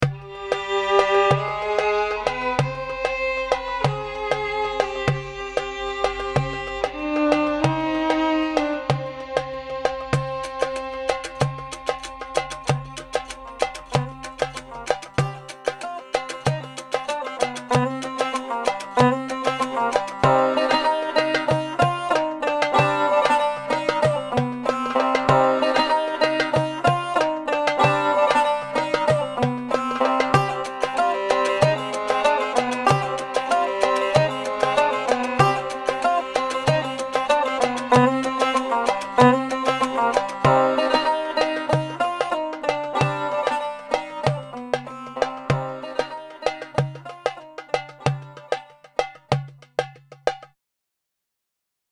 Resource 11: Audio - music to underscore the Five point narrative